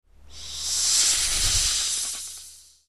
snake.mp3